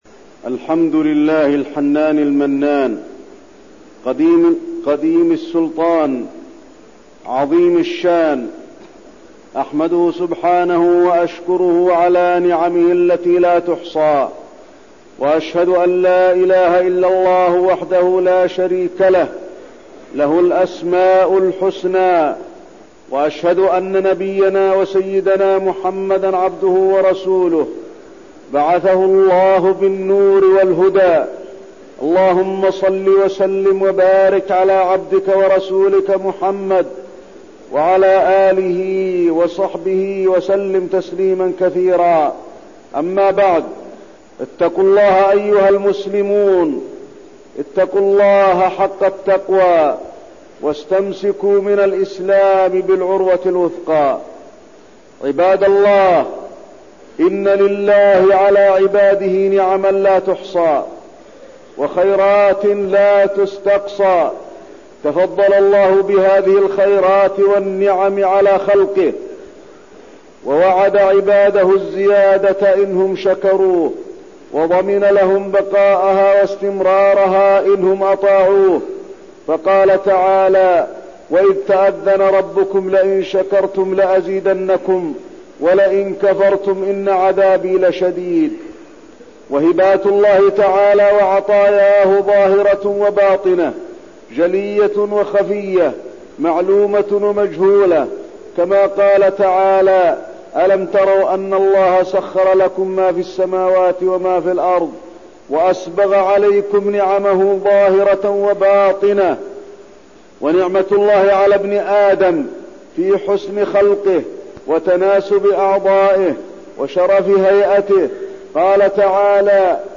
تاريخ النشر ١٨ ذو الحجة ١٤١٢ هـ المكان: المسجد النبوي الشيخ: فضيلة الشيخ د. علي بن عبدالرحمن الحذيفي فضيلة الشيخ د. علي بن عبدالرحمن الحذيفي نعم الله تعالى